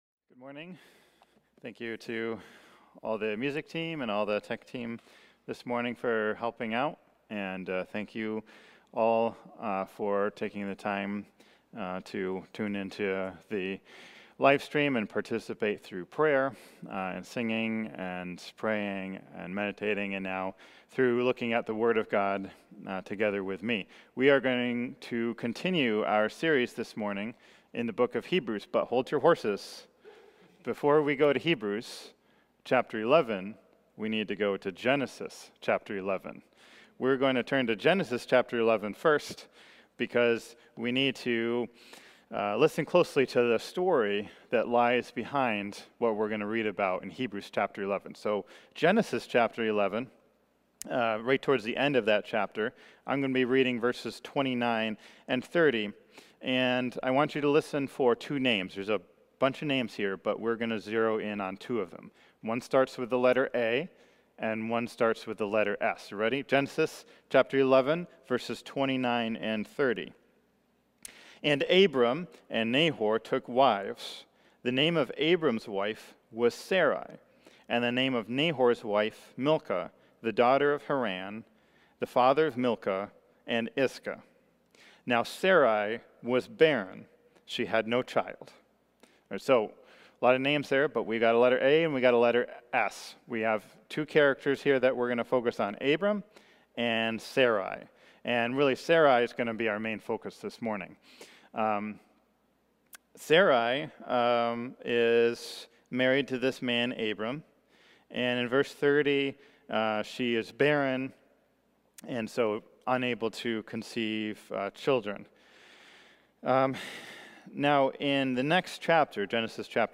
A sermon from Hebrews 11:11–12 in the "Jesus is Better" series.